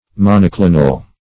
Monoclinal \Mon`o*cli"nal\, a. [See Monoclinic.] (Geol.)